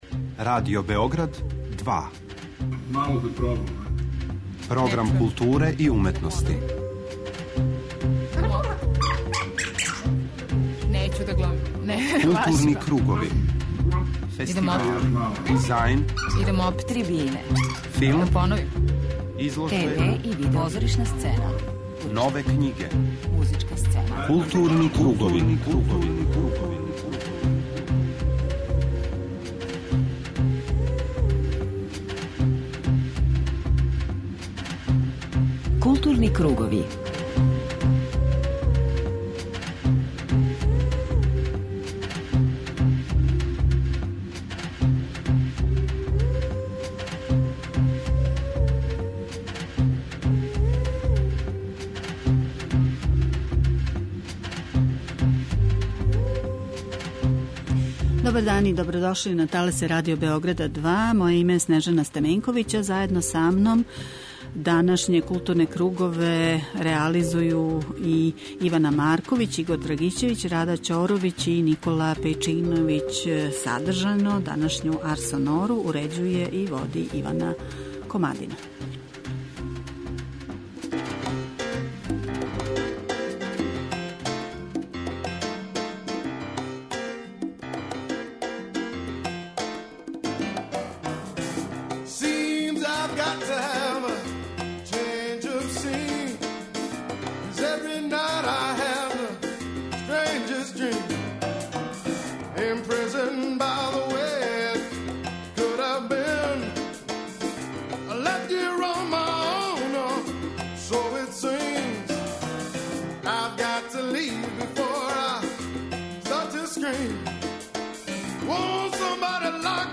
преузми : 41.02 MB Културни кругови Autor: Група аутора Централна културно-уметничка емисија Радио Београда 2.